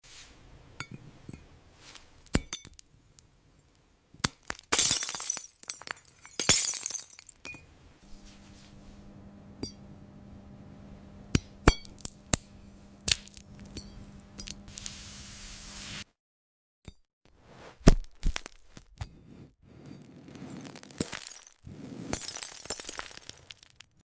Crisp glass cracks, glowing cosmic cores, and hypnotic ASMR that pulls you straight into space. Watch Jupiter, Mars, and Neptune split in pure slicing perfection.